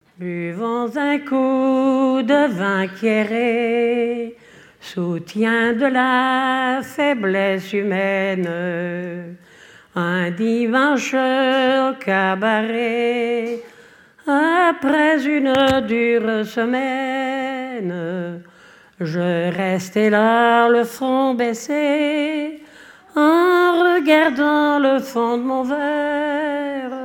Genre strophique
chansons traditionnelles lors d'un concert associant personnes ressources et continuateurs
Pièce musicale inédite